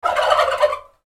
Animal Sounds / Bird Sounds / Sound Effects 6 Nov, 2025 Realistic Turkey Gobble Sound Effect For Thanksgiving Videos Read more & Download...
Realistic-turkey-gobble-sound-effect-for-thanksgiving-videos.mp3